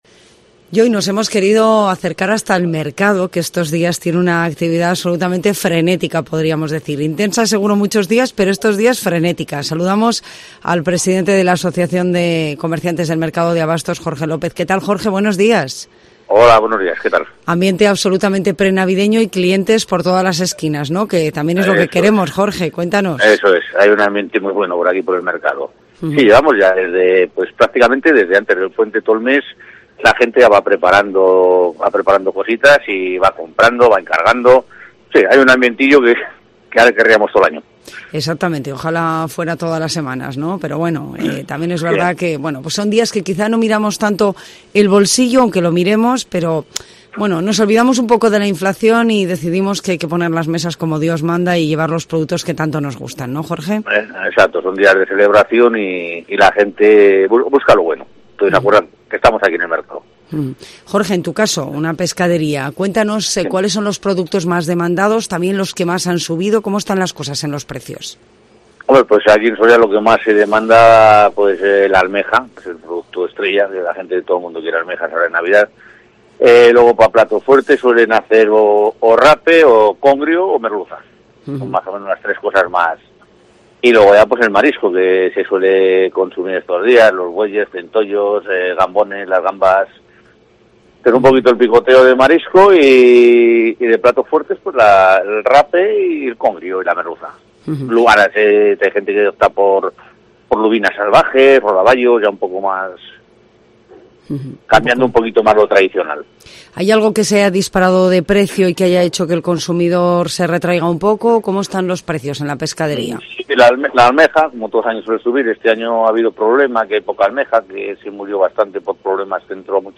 AUDIO: Acércate con nosotros al mercado municipal de abastos de Soria para conocer las preferencias de los sorianos en las mesas de Navidad